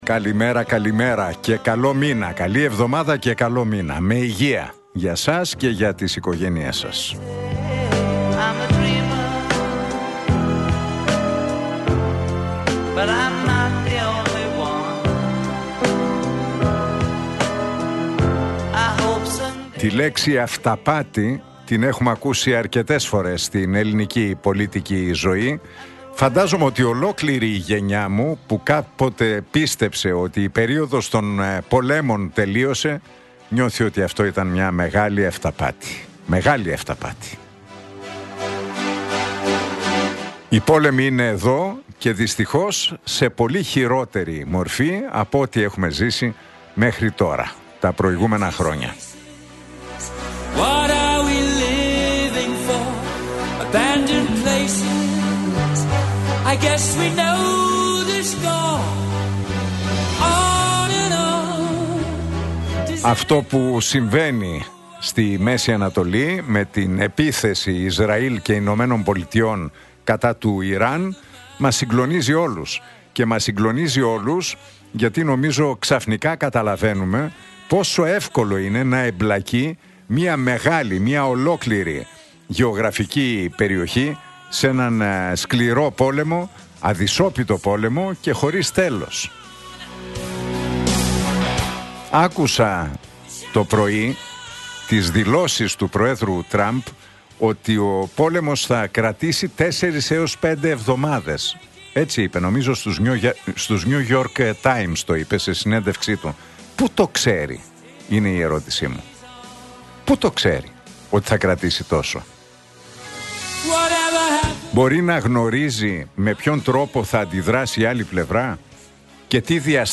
Ακούστε το σχόλιο του Νίκου Χατζηνικολάου στον ραδιοφωνικό σταθμό Realfm 97,8, τη Δευτέρα 2 Μαρτίου 2026.